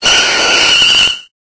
Cri de Rafflesia dans Pokémon Épée et Bouclier.